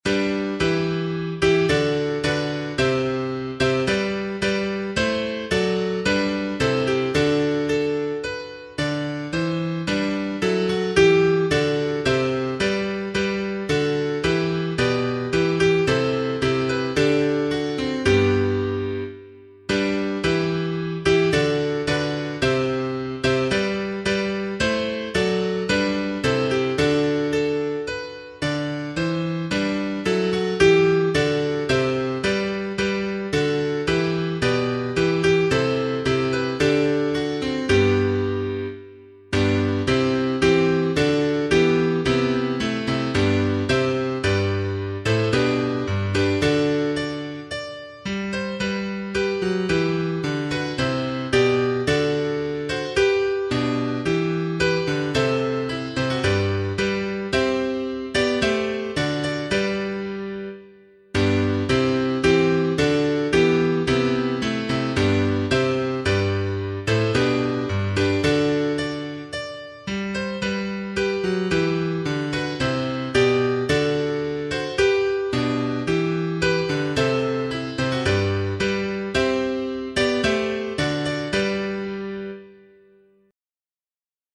MIDI Todos